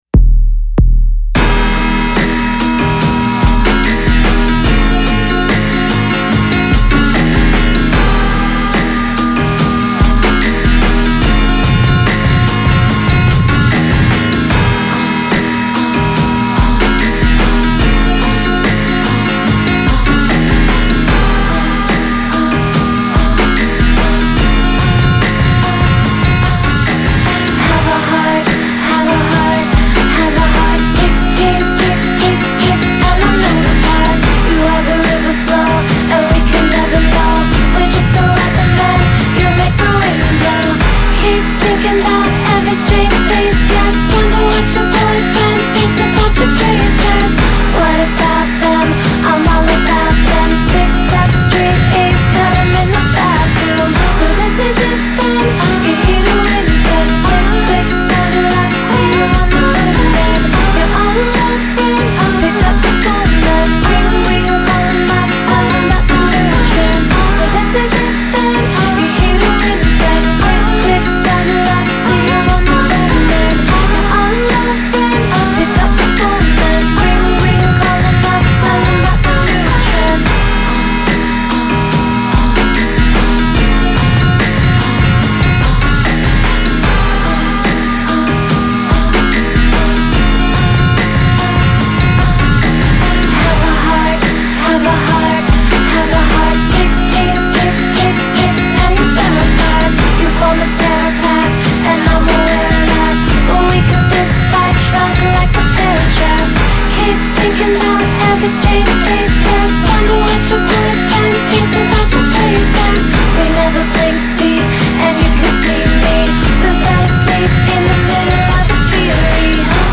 The woman has a great voice 🙂